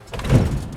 engine-start.wav